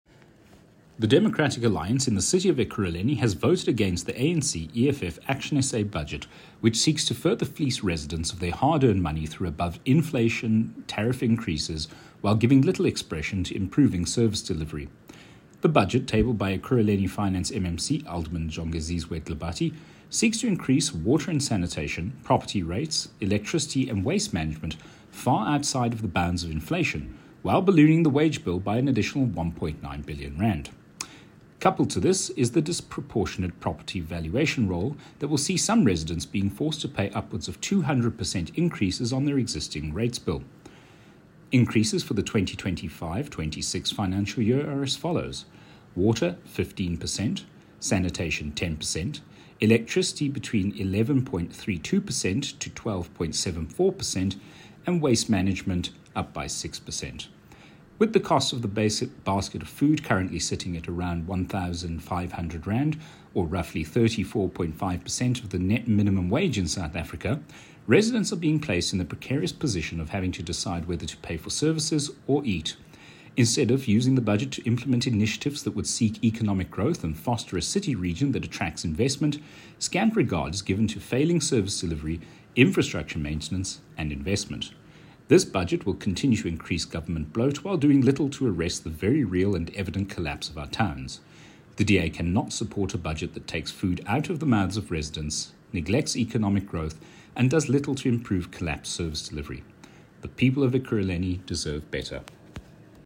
Issued by Cllr Fana Nkosi – DA Ekurhuleni Spokesperson on Finance
Note to Editors: Please find an English soundbite